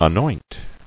[a·noint]